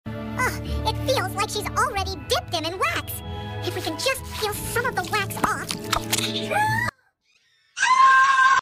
ALSO THE VID IS MINE I JUST REPLACED HIS SCREAM